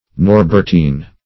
Norbertine \Nor"bert*ine\, n.